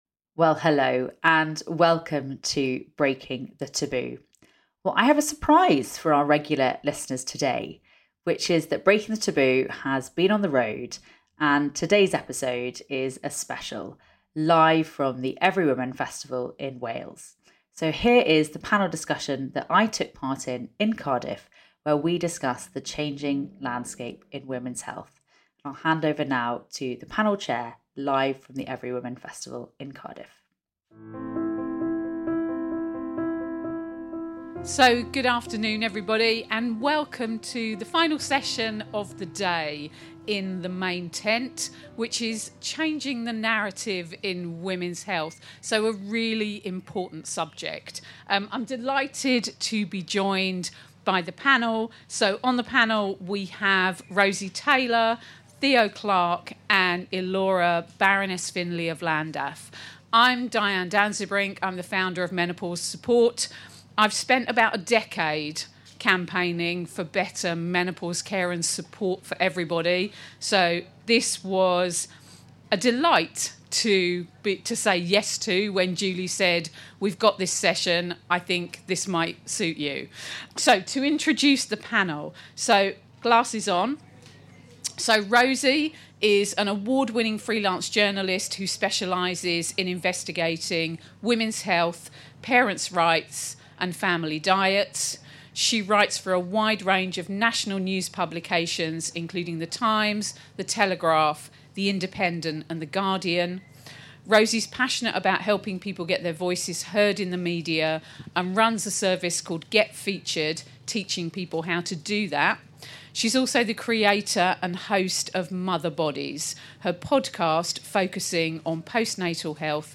She joins the panel on the changing landscape in women's health which was recorded live in the main tent.